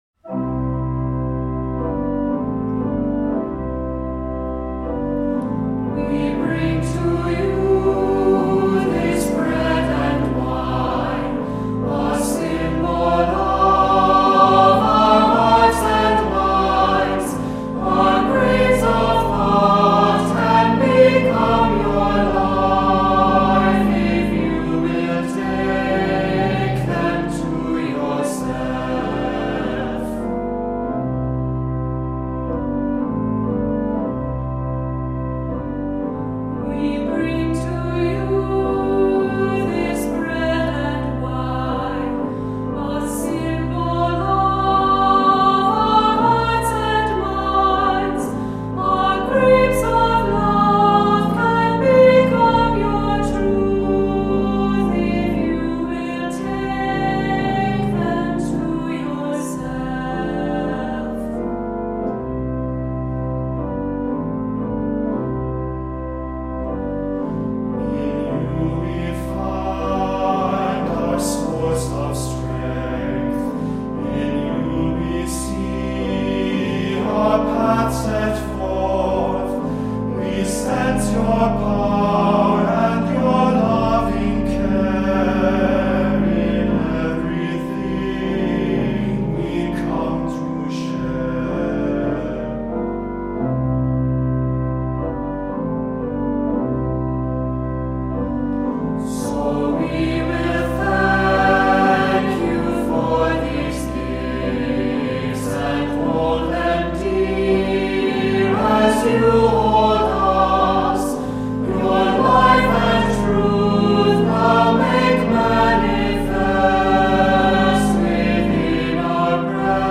Voicing: Assembly, cantor,Unison Choir